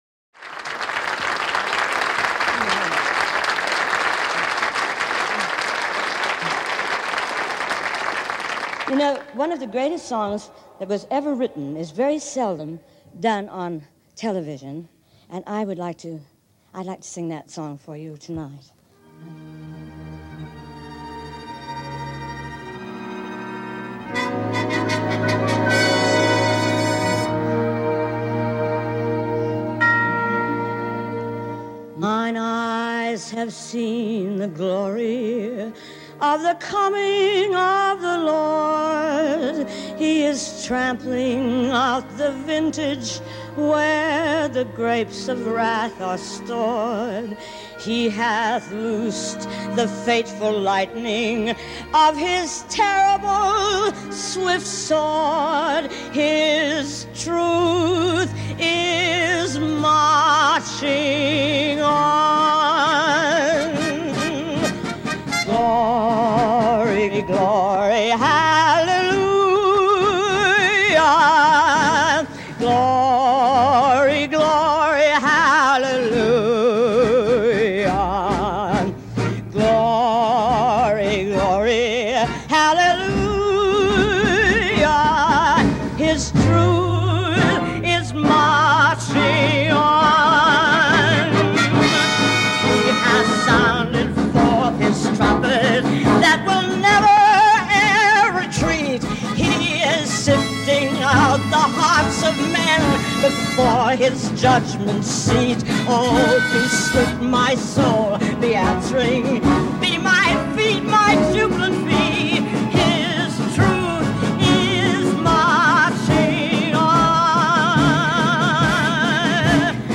1861   Genre: Classic   Artist